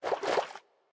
Minecraft Version Minecraft Version latest Latest Release | Latest Snapshot latest / assets / minecraft / sounds / mob / turtle / swim / swim5.ogg Compare With Compare With Latest Release | Latest Snapshot
swim5.ogg